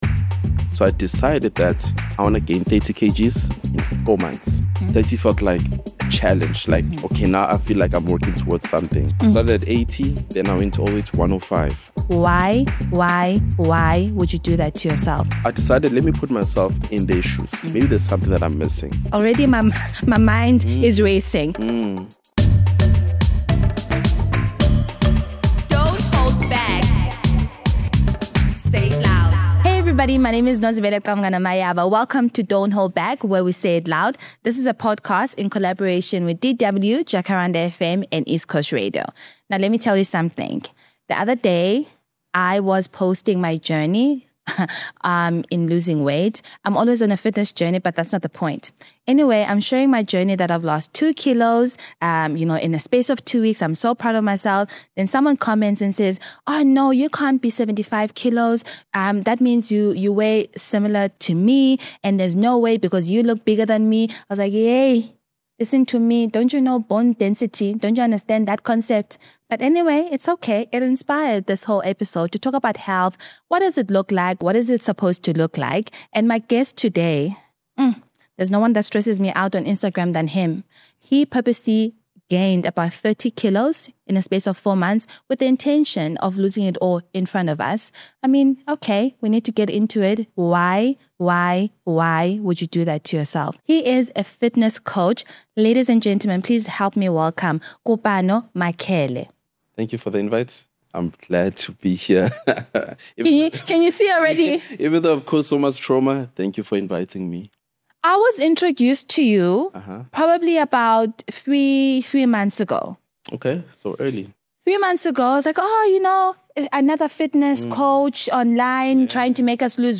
Join her as she chats — and shares a snack — with a different guest in each episode.
… continue reading 27 episodes # Society # Conversations # South Africa # JacPod